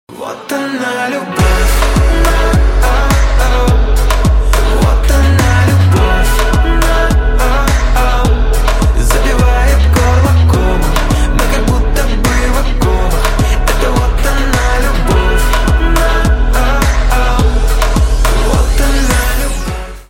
• Качество: 320, Stereo
поп
ритмичные
мужской вокал
dance